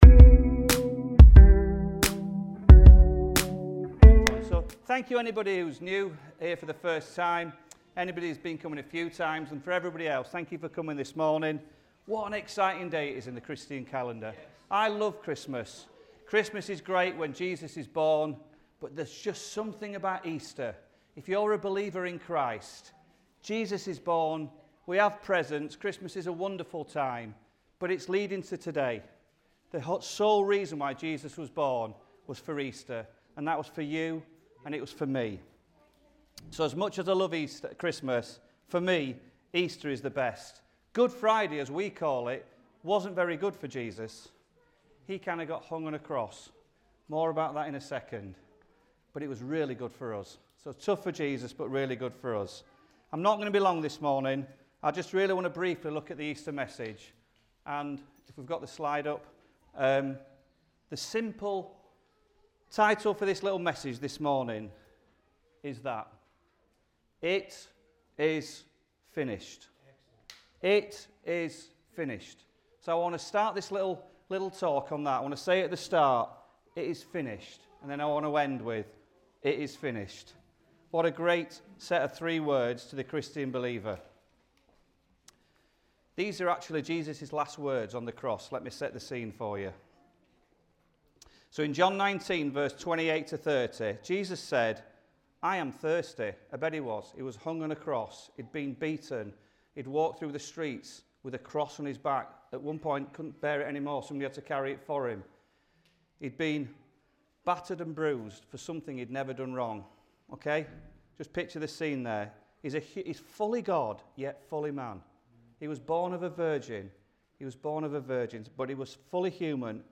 Sunday Messages It Is Finished